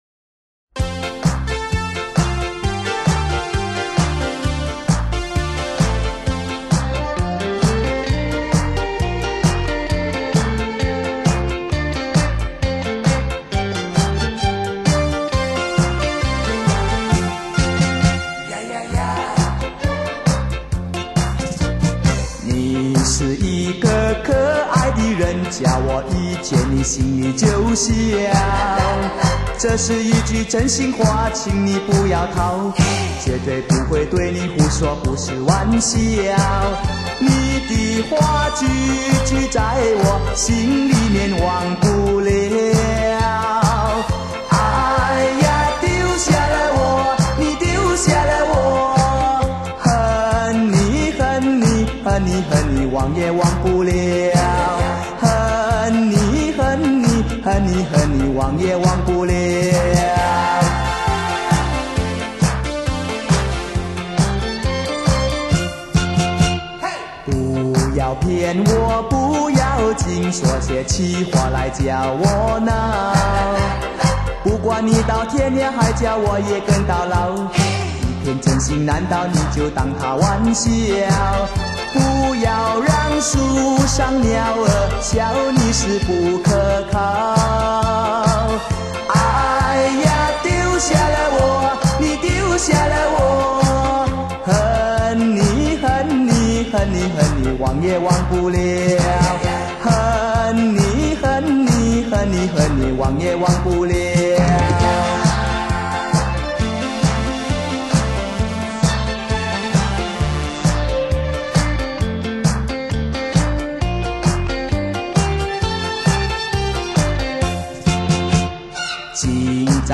声线高亢明亮，翻唱的老歌甜